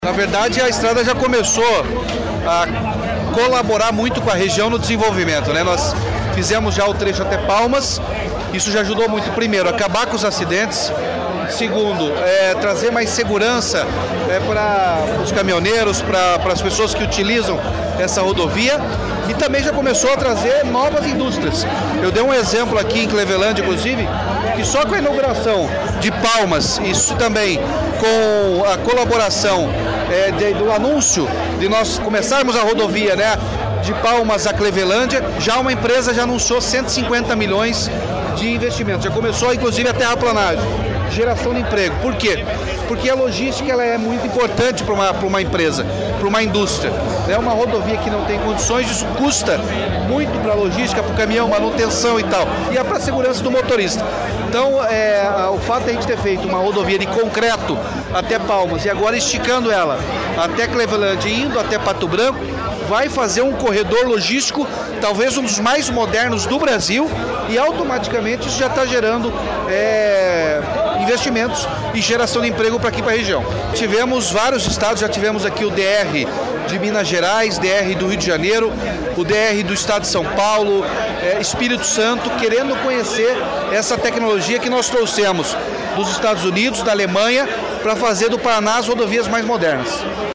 Sonora do governador Ratinho Junior sobre a revitalização da PRC-280 em concreto de Palmas a Pato Branco